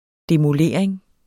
Udtale [ demoˈleɐ̯ˀeŋ ]